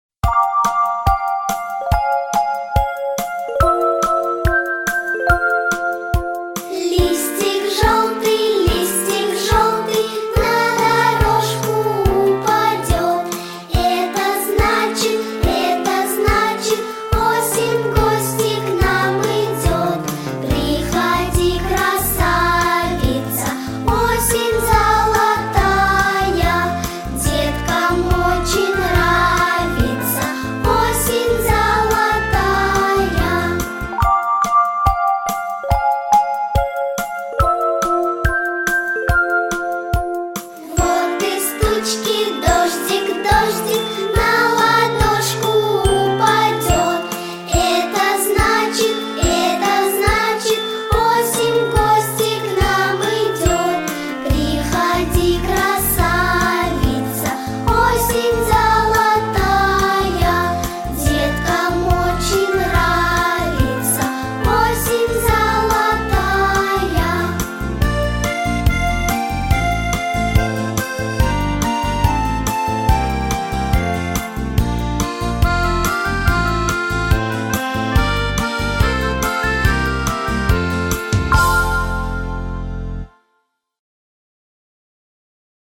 Главная / Песни для детей / Песни про осень